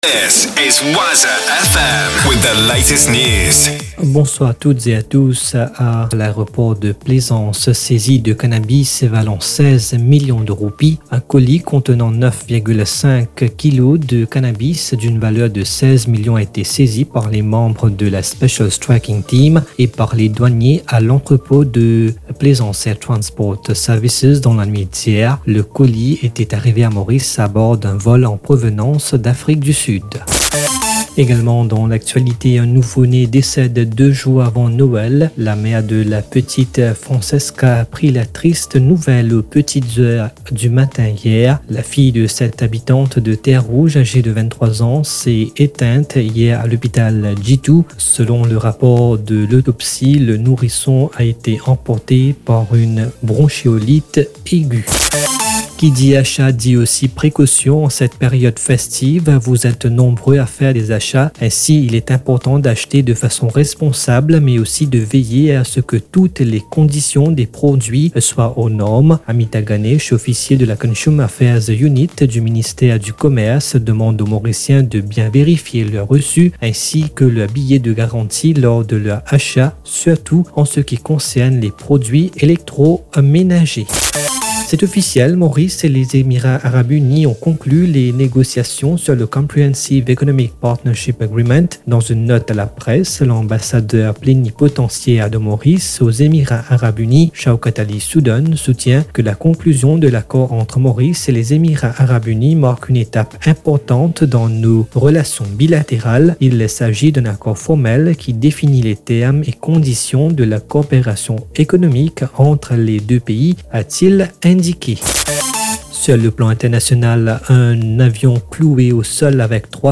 NEWS 17H - 24.12.23